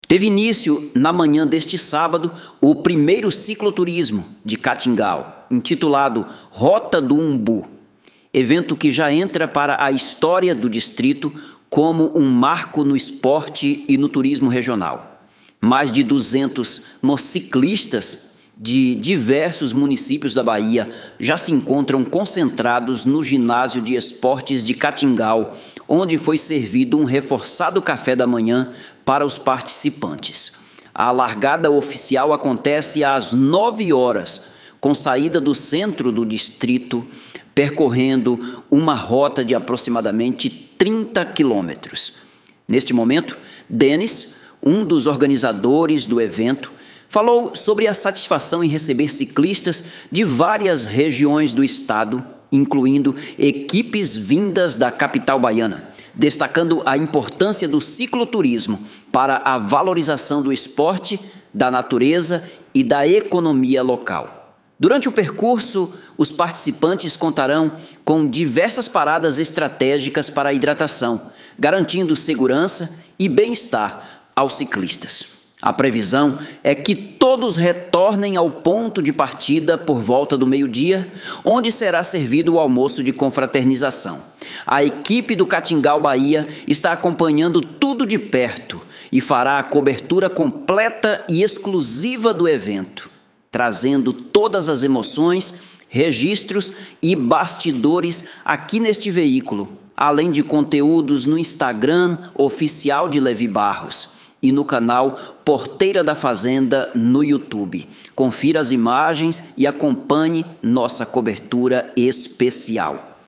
Reportagem.mp3